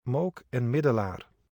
Mook en Middelaar (Dutch: [ˈmoːk ɛ(m) ˈmɪdəlaːr]